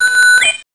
bitewarn.wav